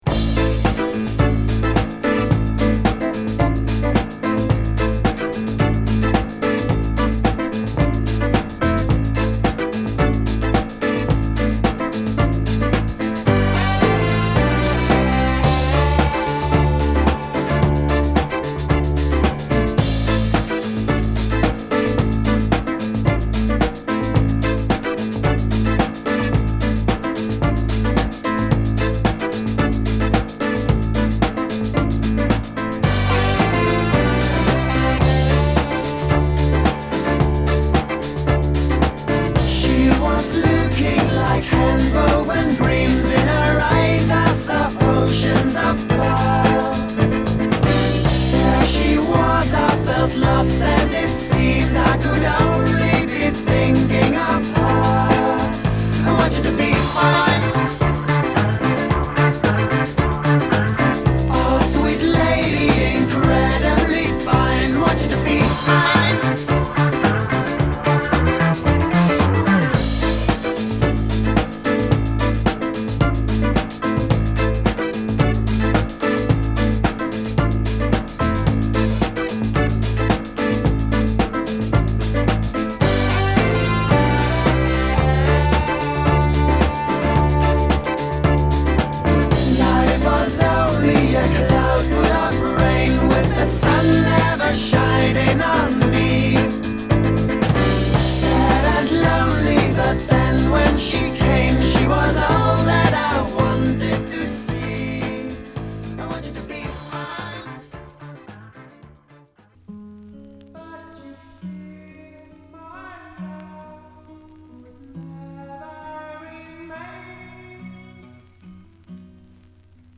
オランダ発のプログレッシヴ・ロック・バンド